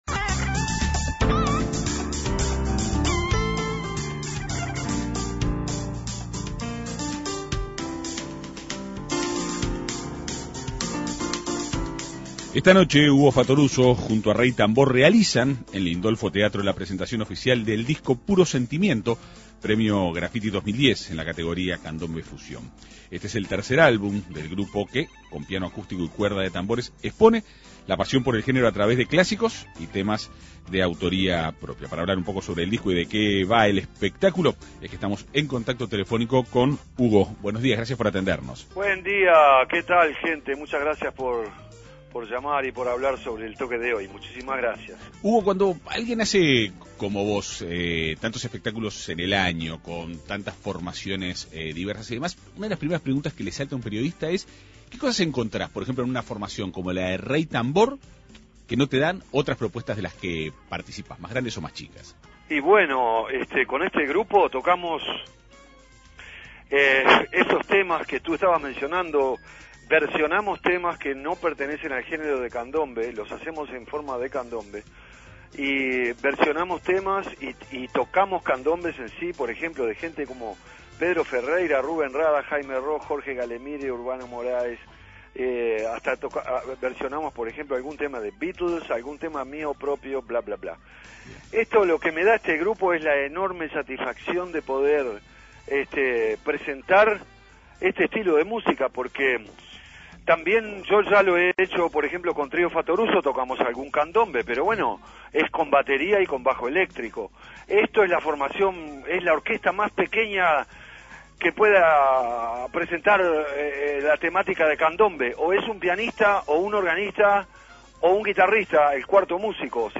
Este es el tercer álbum del grupo que, con piano acústico y cuerda de tambores, expone la pasión por el género a través de clásicos y temas de autoría propia. Para hablar sobre el disco y de qué se tratará el espectáculo, la Segunda Mañana de En Perspectiva conversó con Hugo Fattoruso.